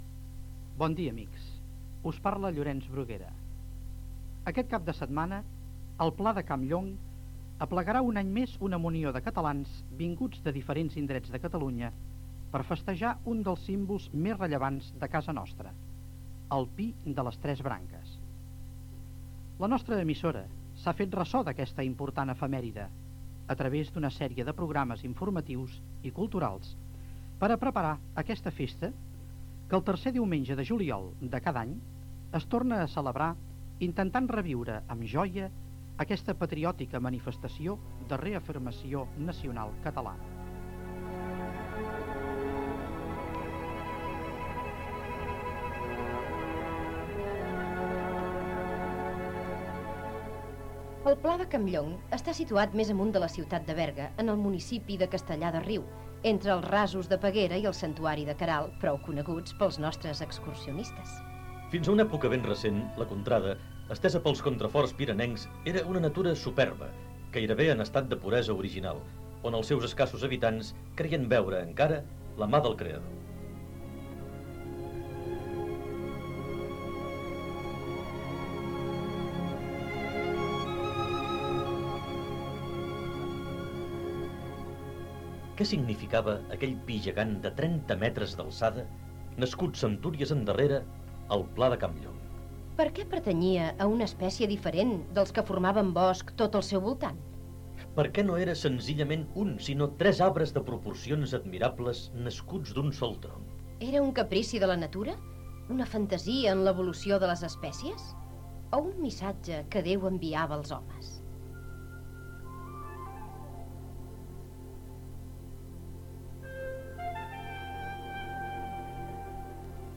717d98ee68f58a4302d6960c186346cf1d855ade.mp3 Títol Ona Cultural Ràdio Emissora Ona Cultural Ràdio Titularitat Tercer sector Descripció El pi de les tres branques, història i descripció de l'indret, sardana i indicatiu de la ràdio.